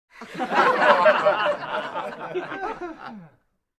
PLAY Lachschleife 2
lachschleife-2.mp3